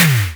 80s Digital Tom 08.wav